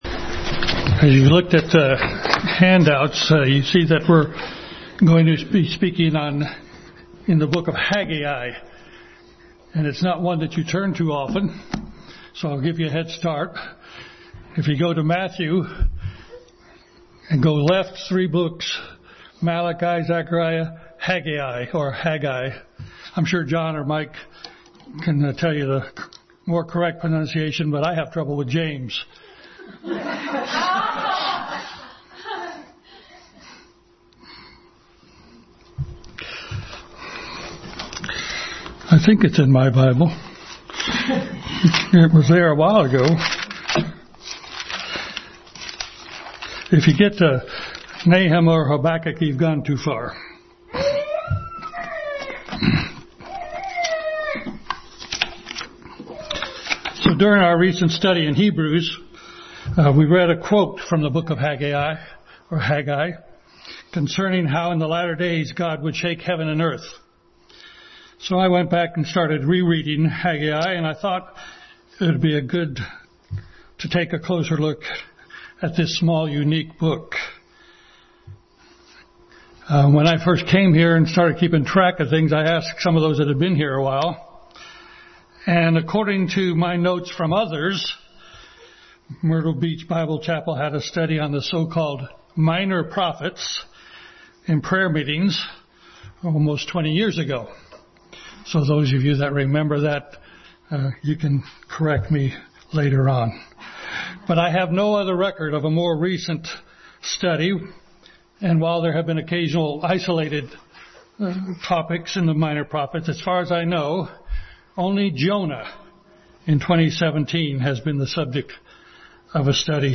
Rebuilding the Temple Passage: Haggai 1:1-15 Service Type: Family Bible Hour